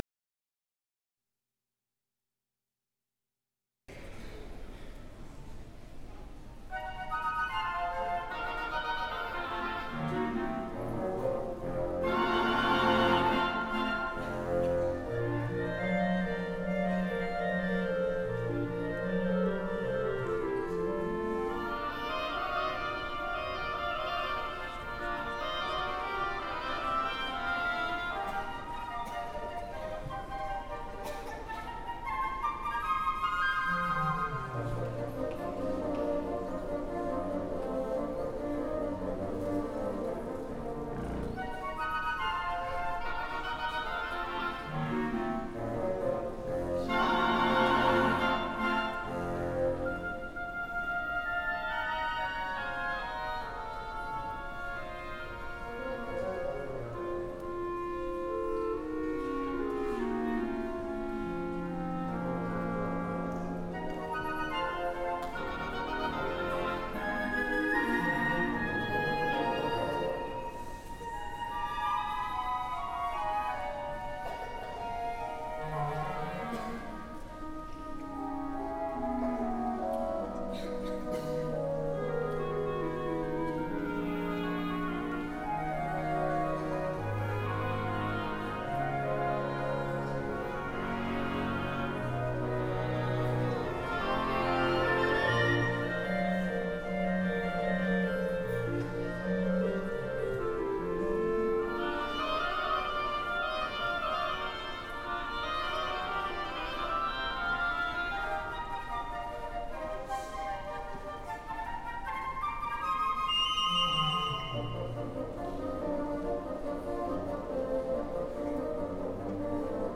Ensemble: Woodwind Ensemble